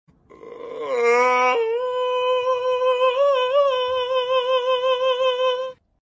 Hahaha Sound Effects Free Download